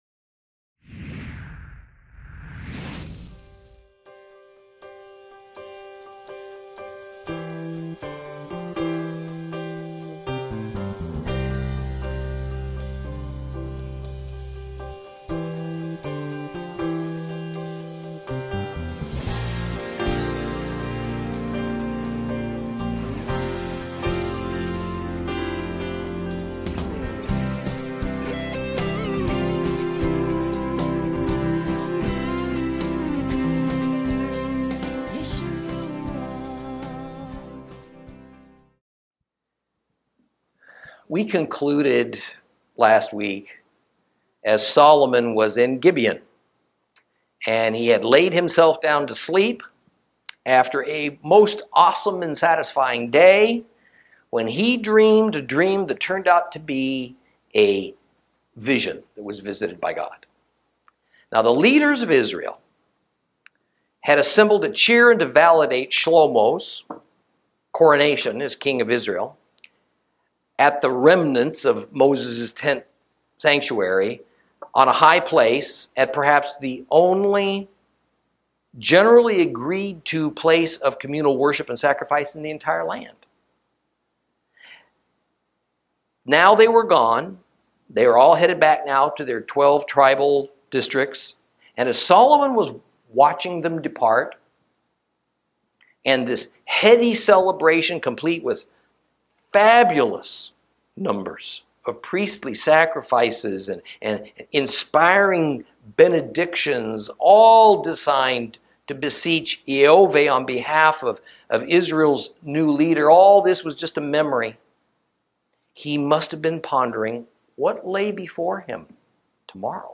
Lesson 7 Ch3 Ch4 - Torah Class